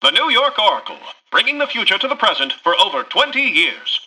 [[Category:Newscaster voicelines]]
Newscaster_headline_06.mp3